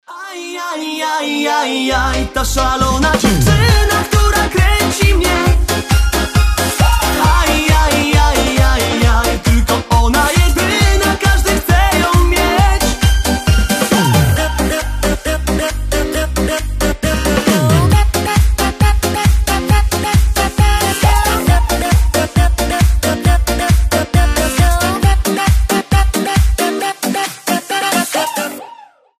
веселые
заводные
Веселая польская песенка